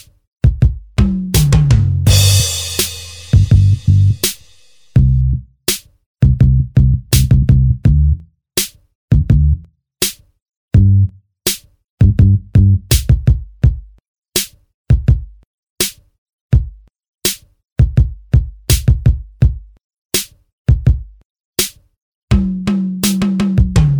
end cut and no Backing Vocals R'n'B / Hip Hop 4:50 Buy £1.50